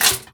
R - Foley 181.wav